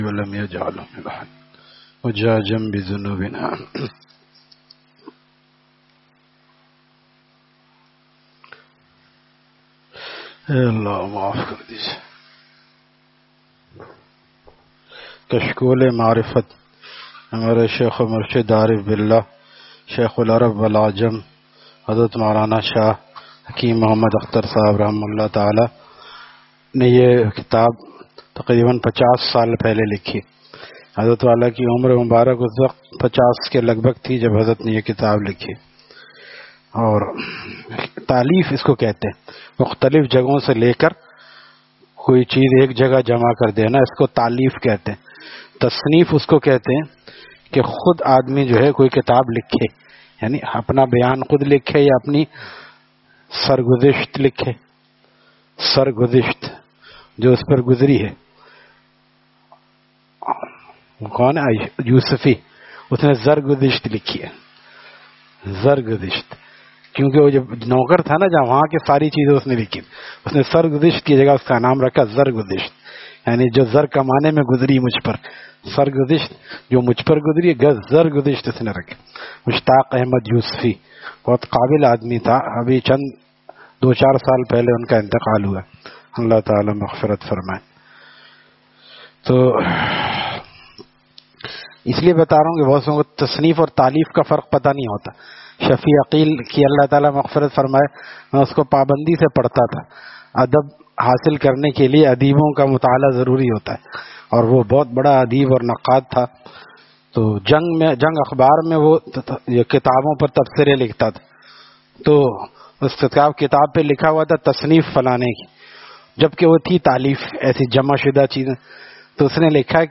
Saturday Markazi Bayan at Jama Masjid Gulzar e Muhammadi, Khanqah Gulzar e Akhter, Sec 4D, Surjani Town